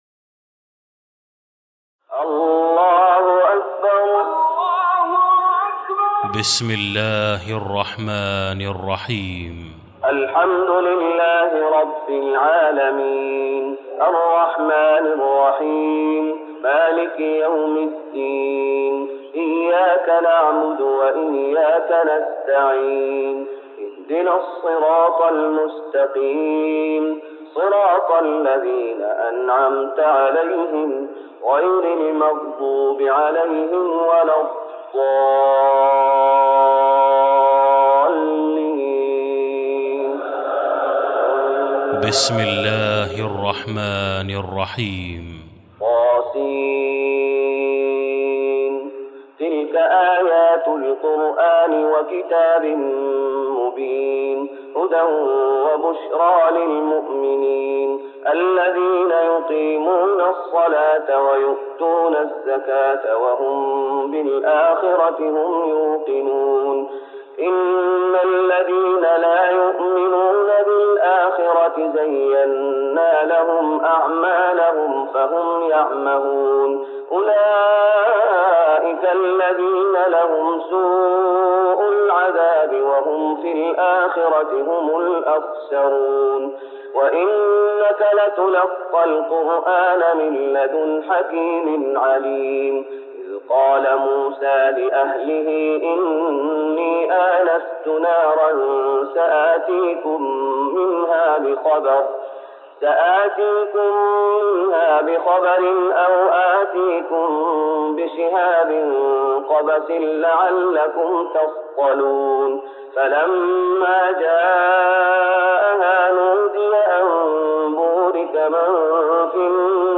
تراويح رمضان 1414هـ من سورة النمل (1-58) Taraweeh Ramadan 1414H from Surah An-Naml > تراويح الشيخ محمد أيوب بالنبوي 1414 🕌 > التراويح - تلاوات الحرمين